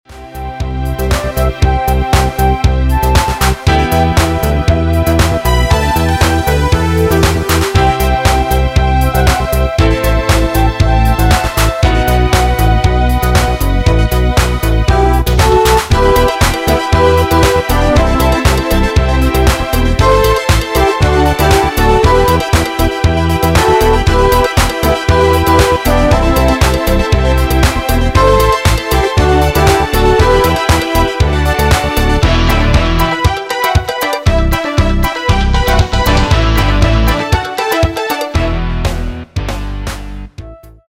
Ecouter un extrait du fichier midi